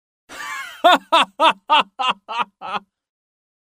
男人哈哈大笑音效_人物音效音效配乐_免费素材下载_提案神器
男人哈哈大笑音效免费音频素材下载